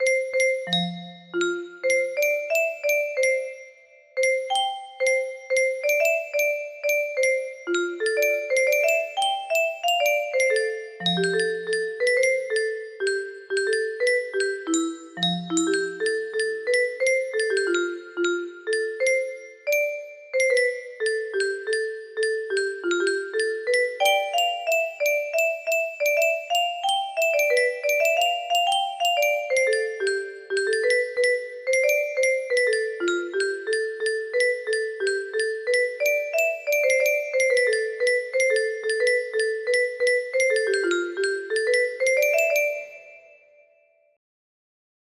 3rd project music box melody
Full range 60